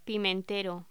Locución: Pimentero
voz